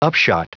Prononciation du mot upshot en anglais (fichier audio)
Prononciation du mot : upshot